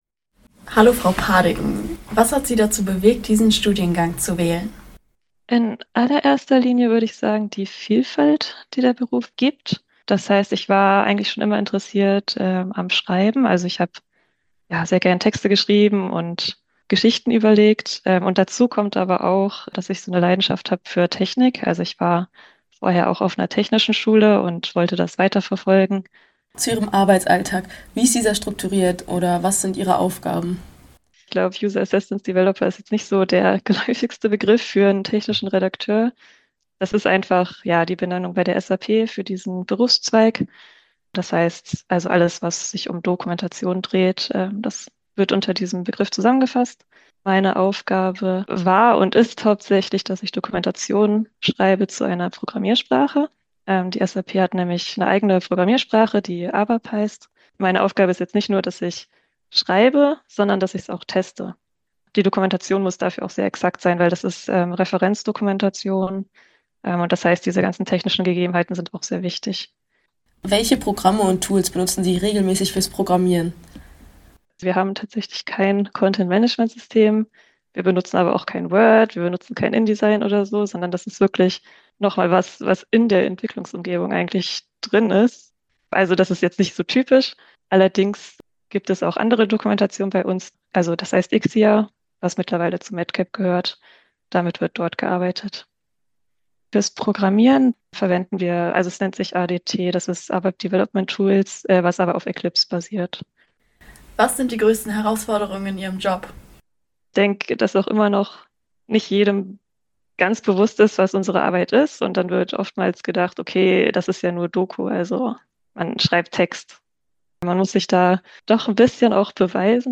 Technische Redakteur*innen im Gespräch